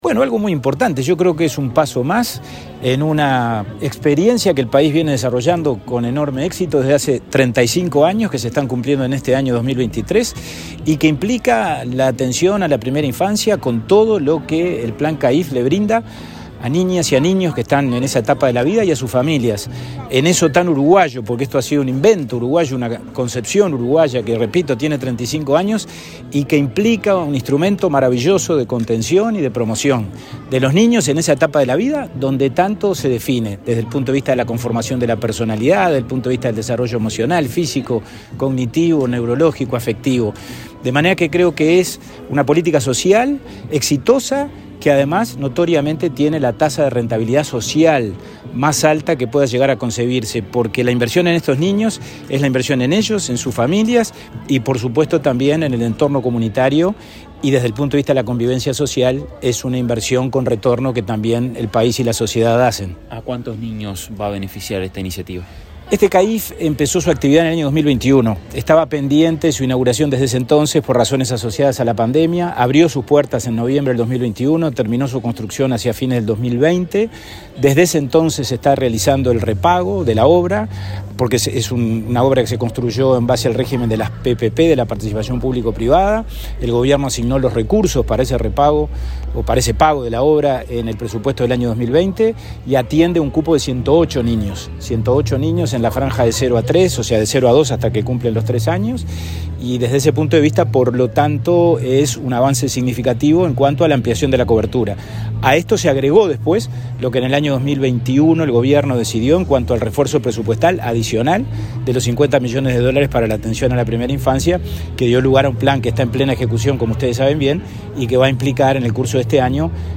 Declaraciones del presidente del INAU, Pablo Abdala
Luego dialogó con la prensa.